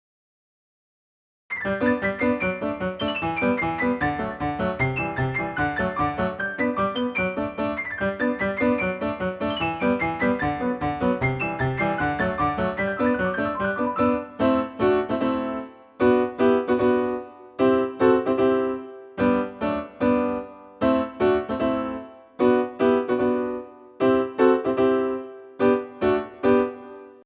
So I wrote a piece on piano that feels similar but doesn’t steal the melody.
piano-can-can.mp3